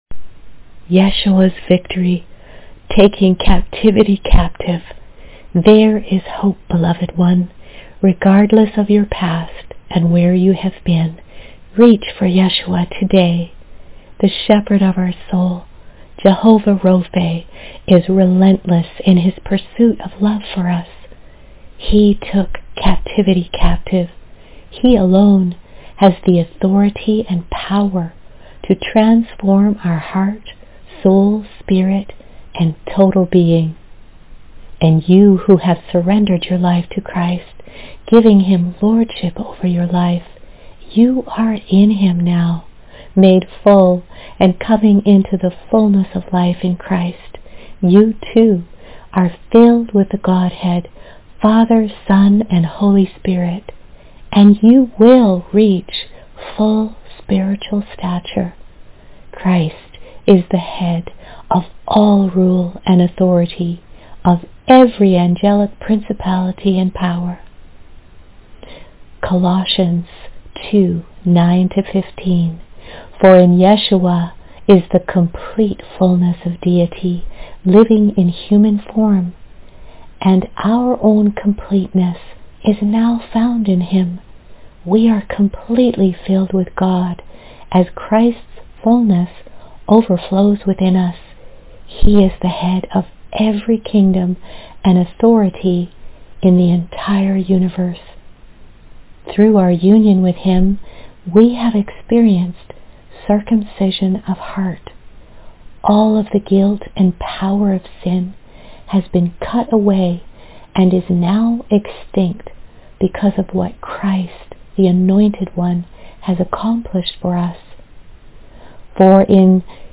Prayer and verse audio from Colossians 2:9-15